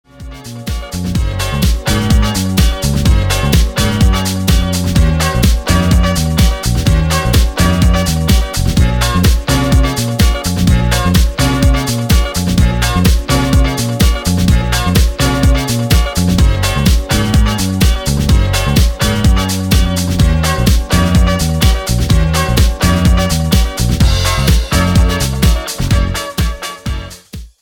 Стиль: French house, nu disco Ура!